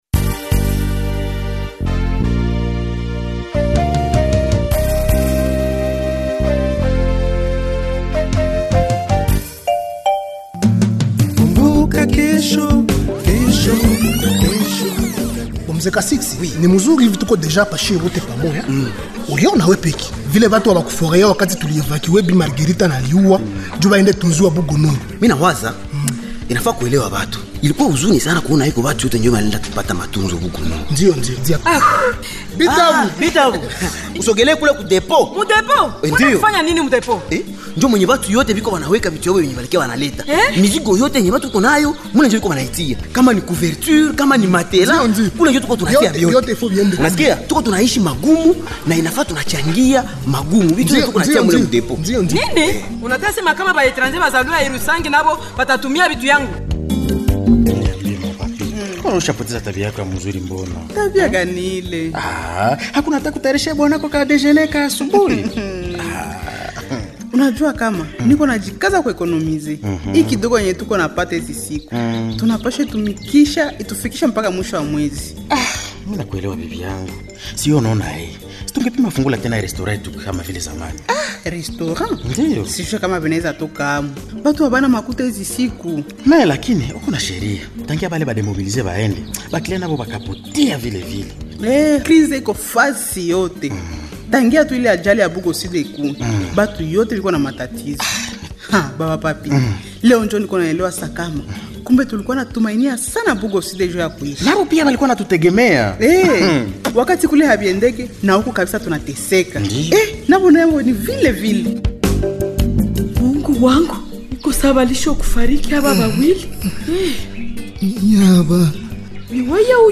Voici le 976e numéro du feuilleton Kumbuka Kesho du 02 au 08 mars 2026
Retrouvez ici le feuilleton Kumbuka Kesho que vous pouvez suivre sur toutes les radios parteraires de LaBenevolencija ou simplement en cliquant sur ce son :